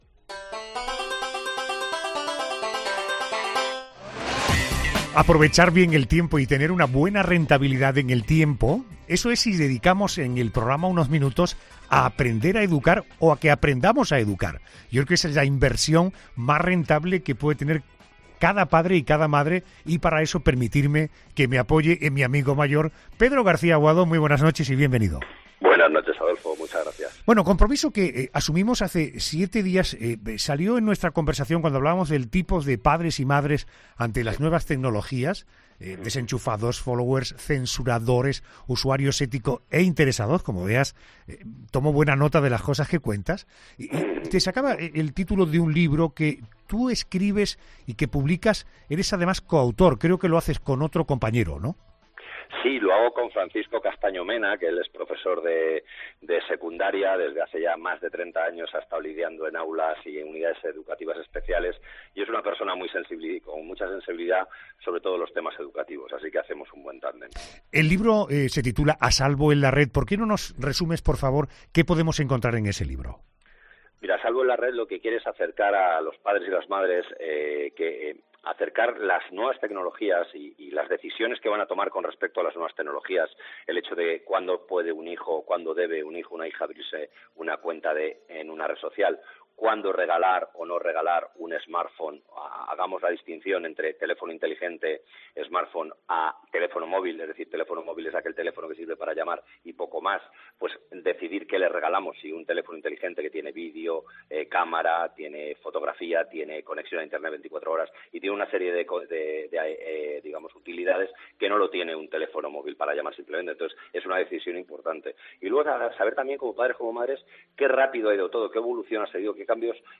El intervencionista familiar de ‘La Noche de COPE’, Pedro García Aguado, sigue enseñándonos cómo aprender a educar a nuestros hijos.